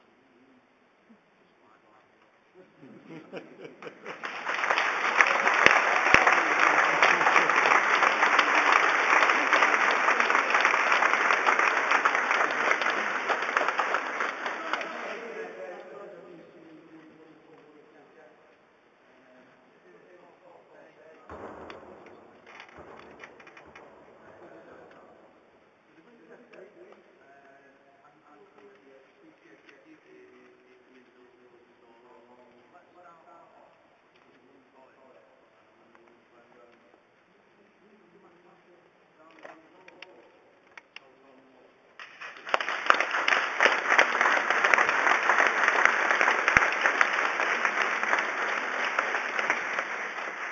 Applause, Farewell in Central Library.